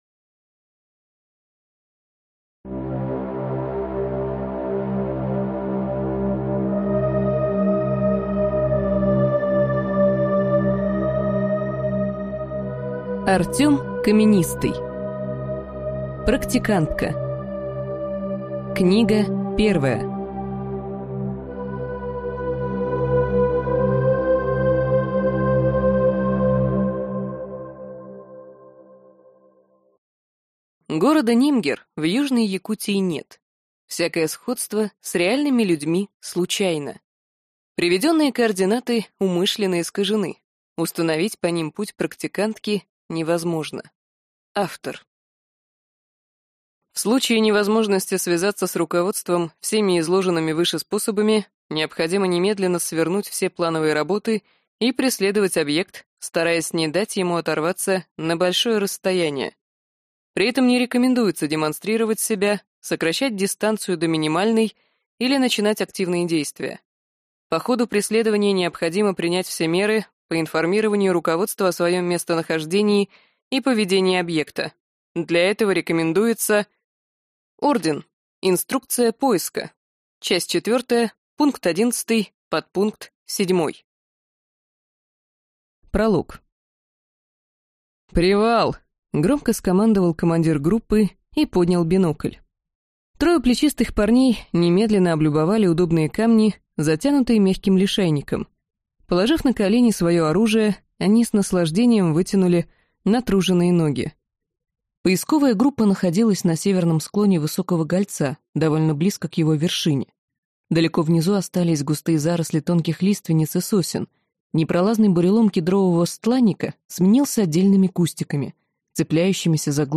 Аудиокнига Практикантка | Библиотека аудиокниг